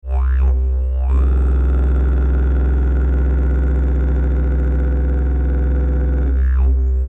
donc je suis parti sur une note de base du didge en Do, puis j'ai monté la gamme en vocalises dedans, demi-ton par demi-ton.....
Re#
je vous laisse entendre les différents frottements que ça donne.....bon je chante pas non plus super juste, donc des fois ça fluctue un peu, mais ça devrait suffir pour se faire une idée... :mrgreen: